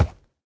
minecraft / sounds / mob / horse / wood3.ogg
wood3.ogg